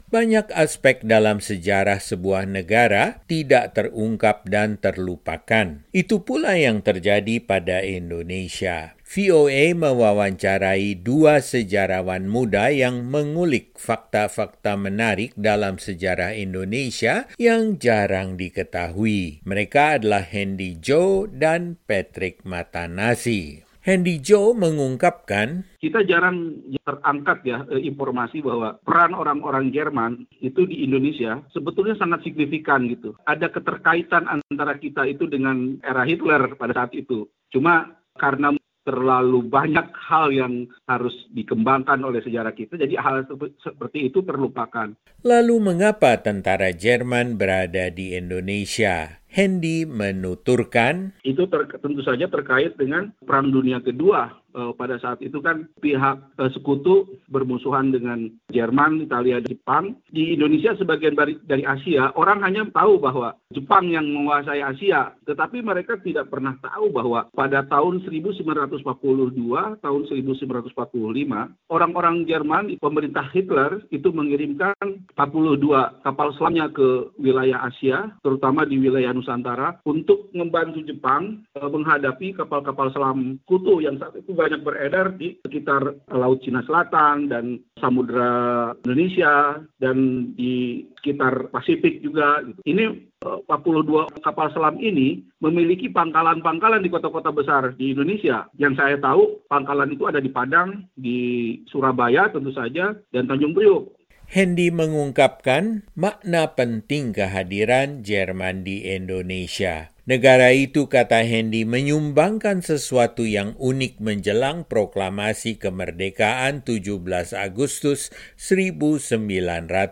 VOA mewawancarai dua sejarawan muda yang mengulik fakta-fakta menarik dalam sejarah Indonesia yang jarang diketahui.